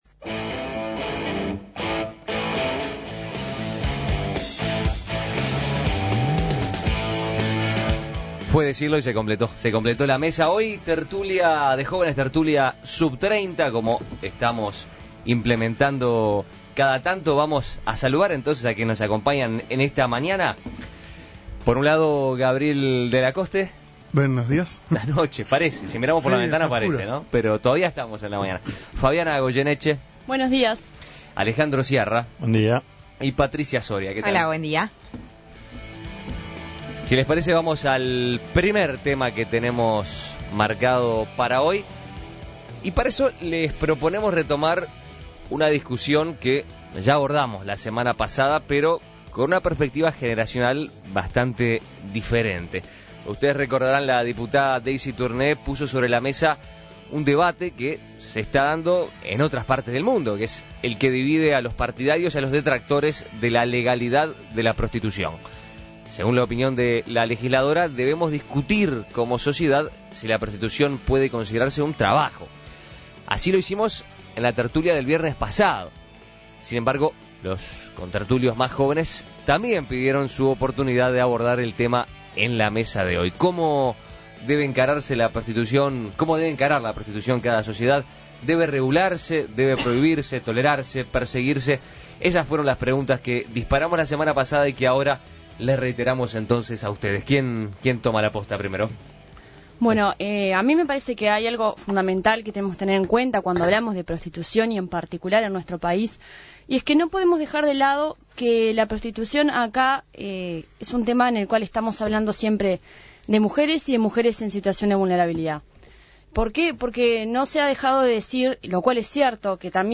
Según la opinión de la legisladora, debemos discutir como sociedad si la prostitución puede considerarse un trabajo. El tema ya fue abordado en La tertulia de los viernes, pero los contertulios sub-30 también pidieron pista para tratarlo en su mesa.